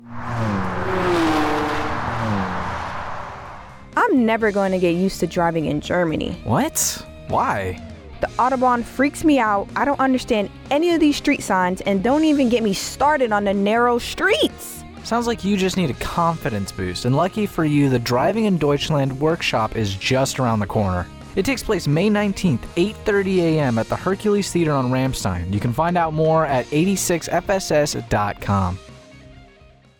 A 30-second radio spot promoting the Driving in Deutschland workshop taking place at the Hercules Theater on Ramstein Air Base that will air from April 14, 2026, to May 19, 2026. This workshop will help service members and their families be more comfortable driving in Germany.